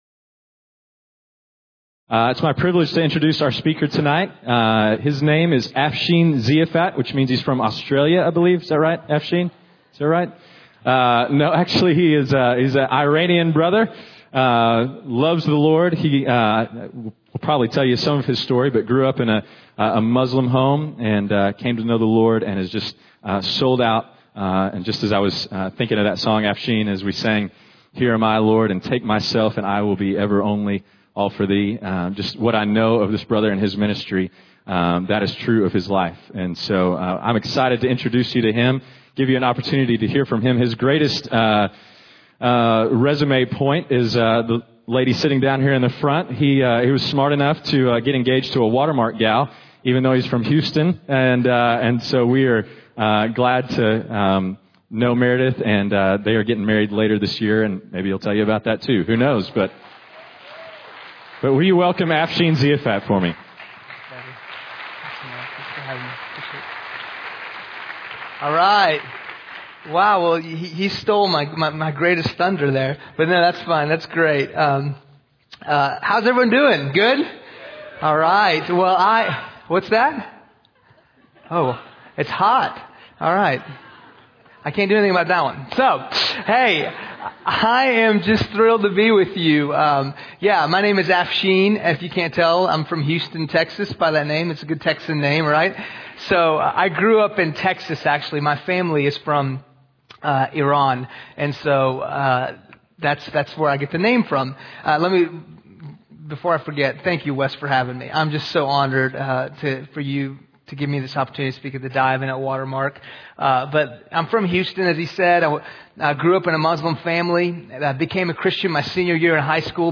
Please listen to this speaker from Church, it is really an amazing speech...